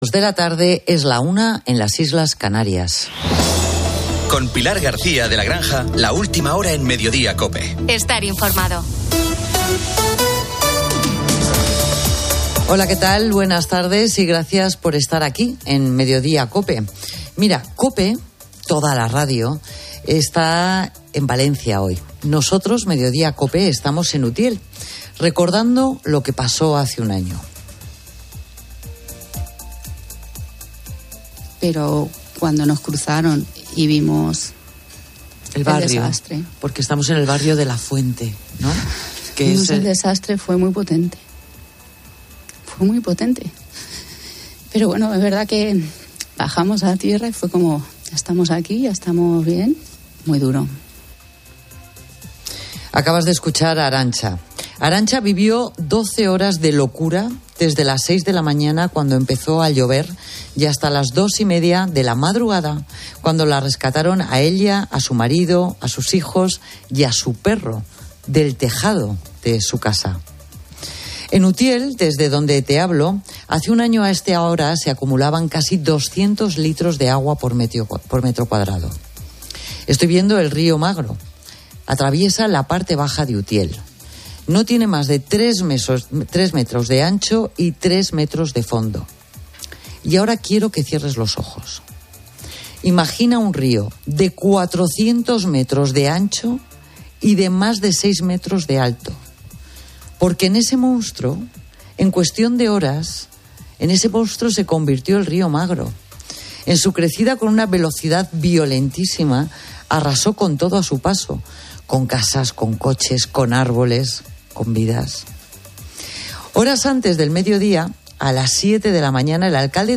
Un año después de la DANA, COPE emite desde Utiel, donde su alcalde detalla una reconstrucción lenta con daños que superan los 10 millones de euros....